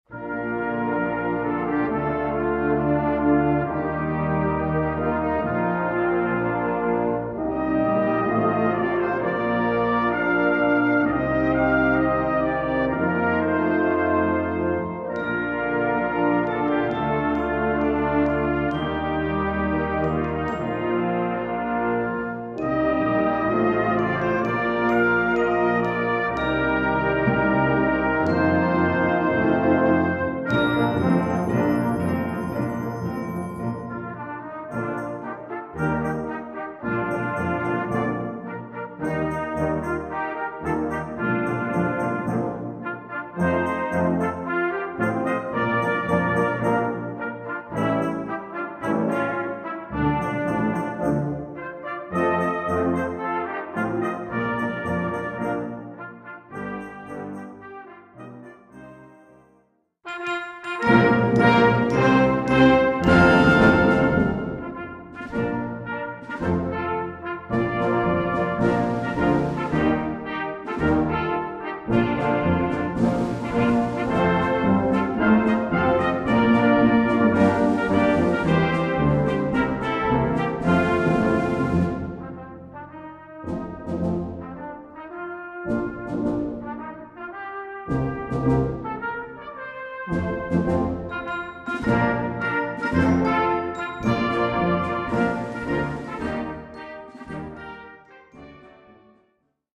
Répertoire pour Brass band - Brass Band